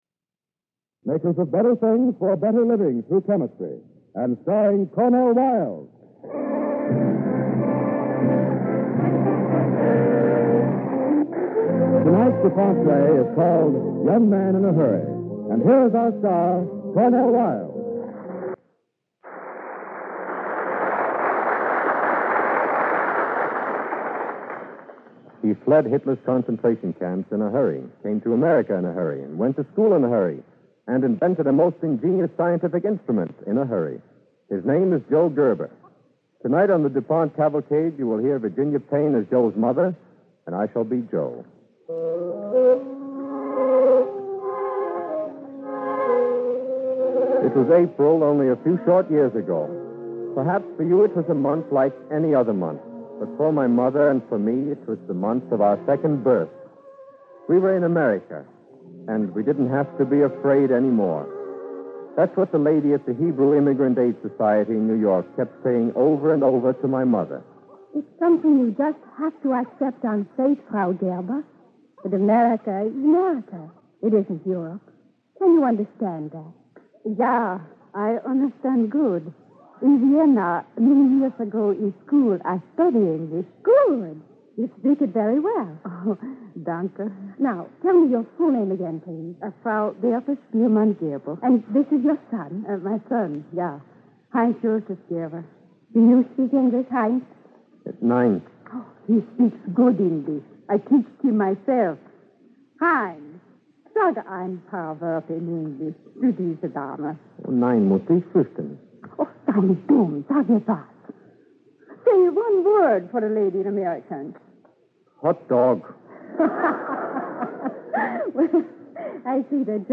Cavalcade of America Radio Program
Young Man in a Hurry, starring Cornel Wilde and Virginia Payne